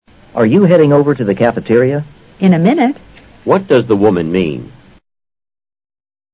托福听力小对话